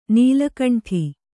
♪ nīla kaṇṭhi